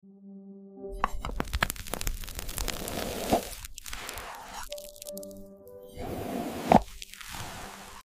ASMR White Fluffy Cloud Video. Sound Effects Free Download